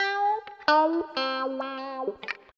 134 GTR 4 -L.wav